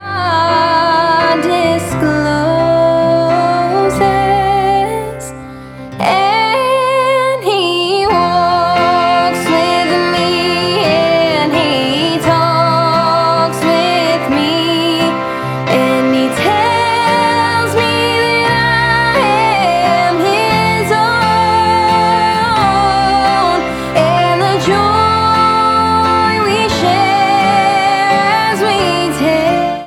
• Country Ringtones